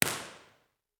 Description:  The O’Reilly Theater is a 650 seats semi-reverberant space located in the famous Pittsburg cultural district. The reverberation time is just under 1 sec, with the acoustics of the space optimized for natural support of on-stage sources.
File Type: B-Format, XY Stereo
Microphone: Core Sound Tetramic
Source: 14 sec log sweep
Test Position 1 (above)
XY-Stereo Room Impulse Responses (zip file 300 kB)
IR_TP1_XYSTEREO_OReilly.wav